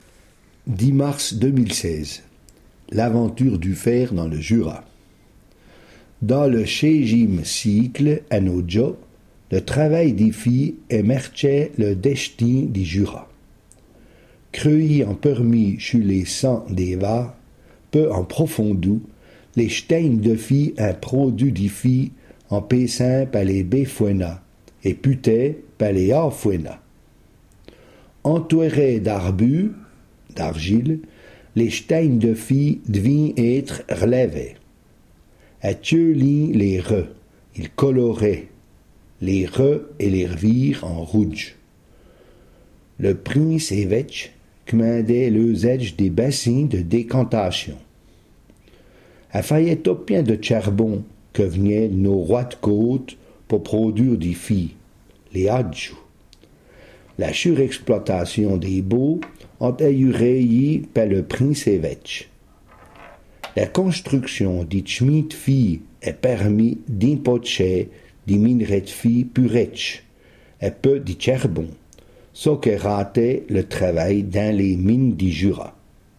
Ecouter le r�sum� en patois